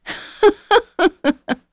Seria-Vox_Happy1_kr.wav